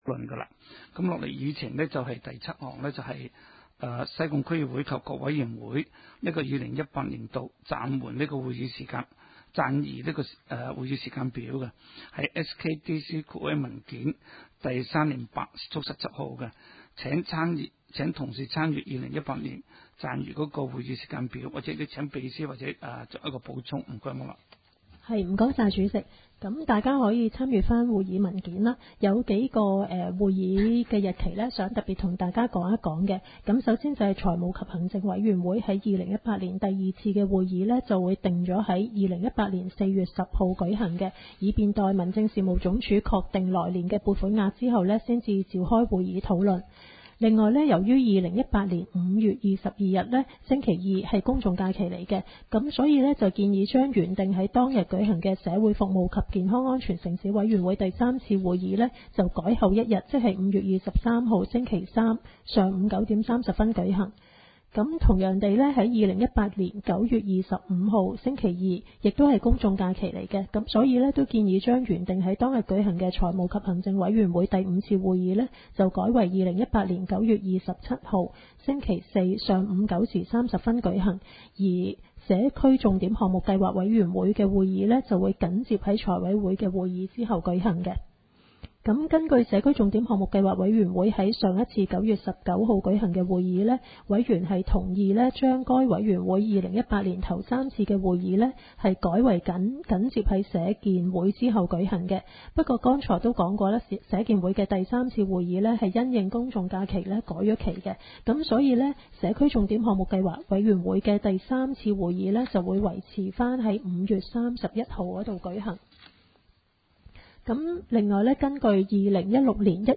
区议会大会的录音记录
地点: 将军澳坑口培成路38号
西贡将军澳政府综合大楼
三楼会议室